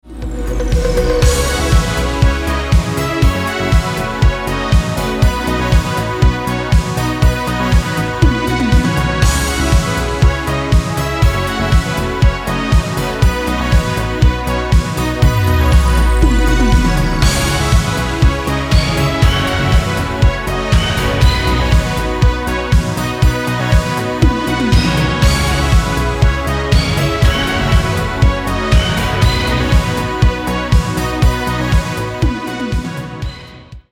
• Качество: 192, Stereo
Synth Pop
спокойные
без слов
спокойная мелодия
Классная музыка с ретро звучанием